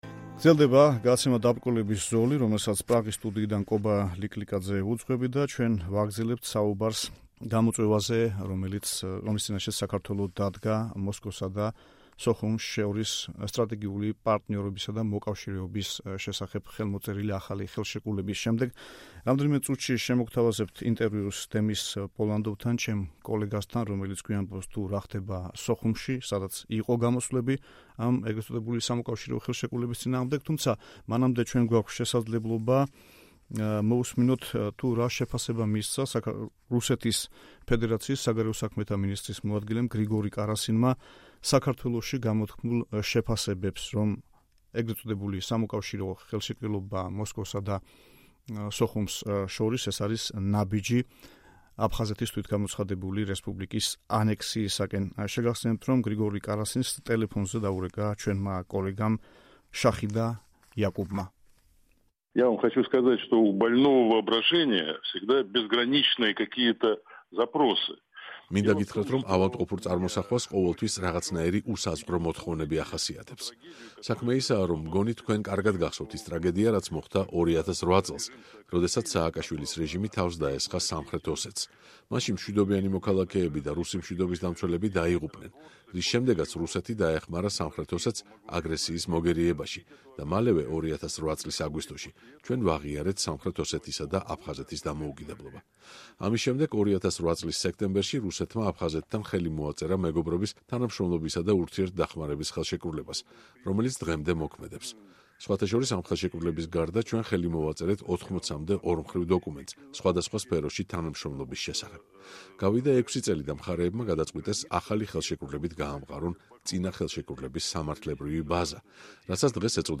ინტერვიუ გრიგორი კარასინთან